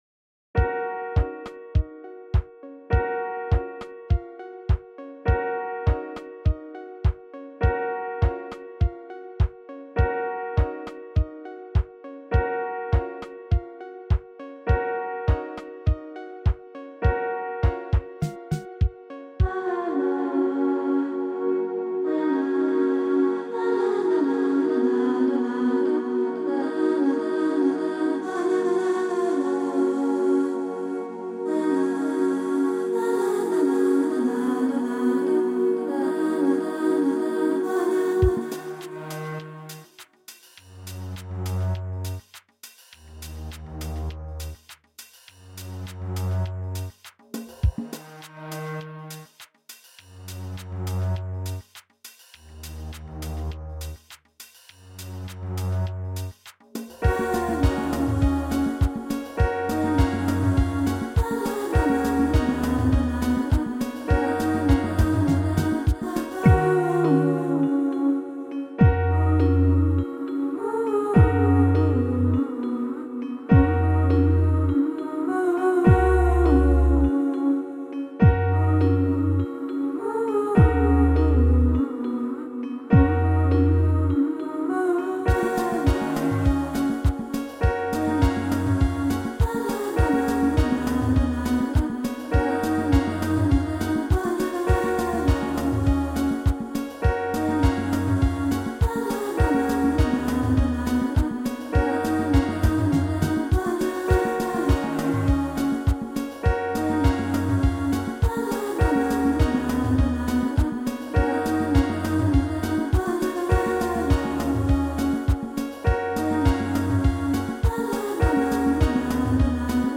un album musical composé par l’intelligence artificielle